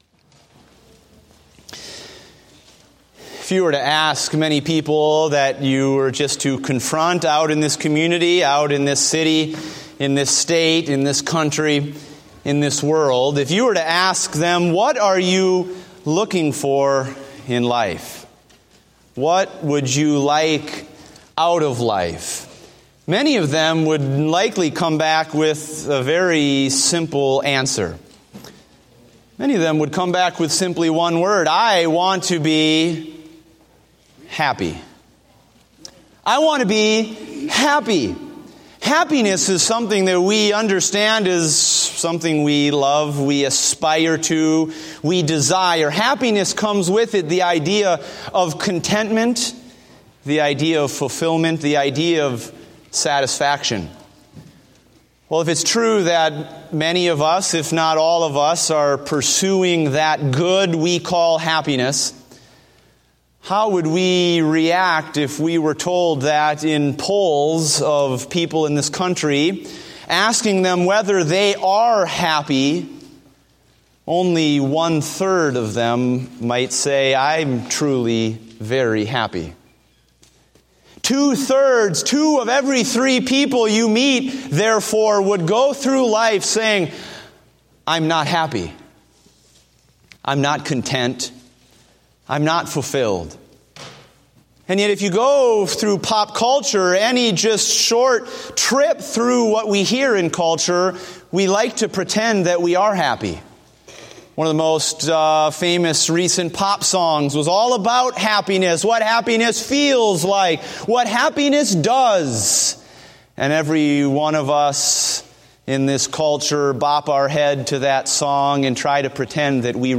Date: February 7, 2016 (Morning Service)